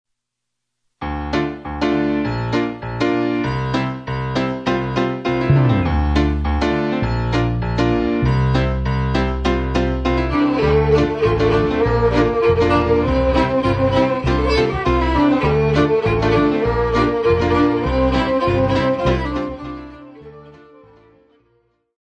who played Celtic, French-Canadian and original music